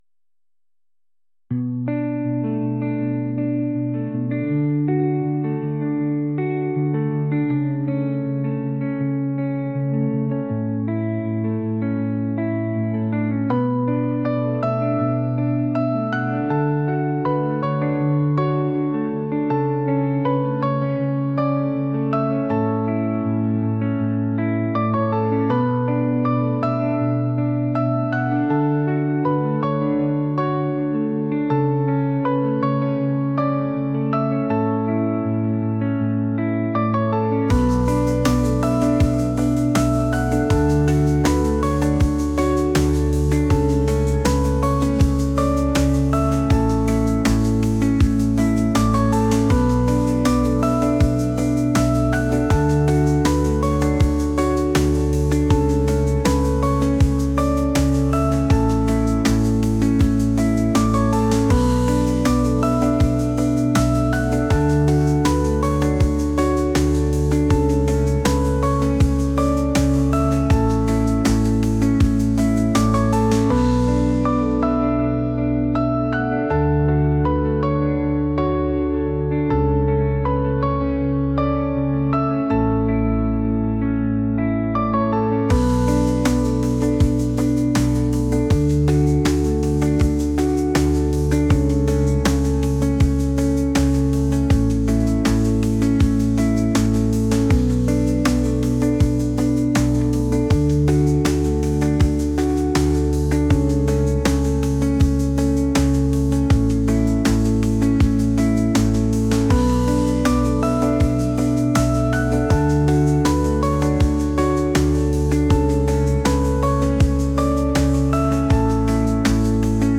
dreamy | atmospheric | indie | pop